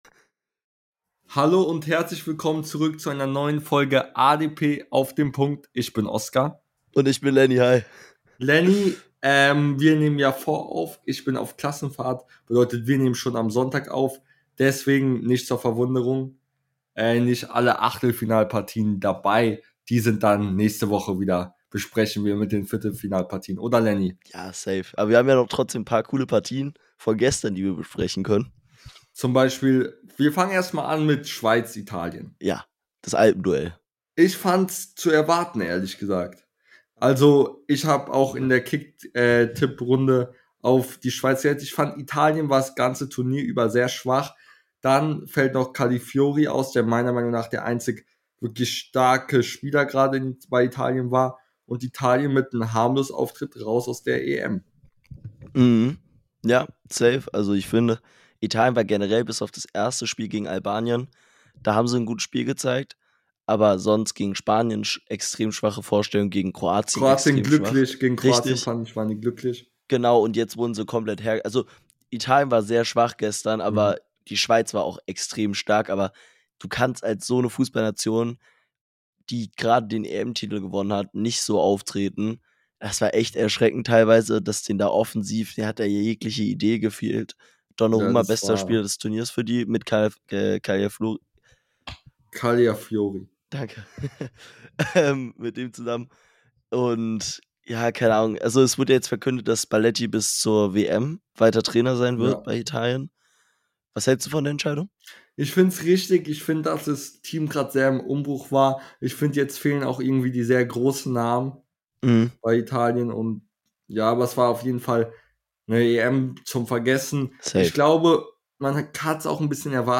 In der heutigen Folge reden die beiden Hosts über die ersten Achtelfinale , die Enttäuschungen und Überraschungen der Gruppenphase und vieles mehr